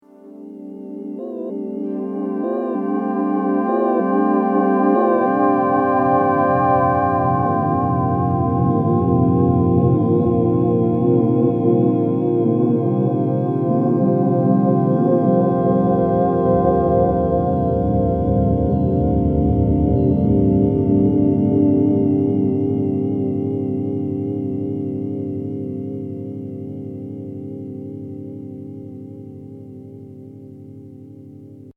Fretless with polyponic aftertouch
Tags: Roland U-110 Synth sounds Roland U-110 sample Roland Roland U-110 sounds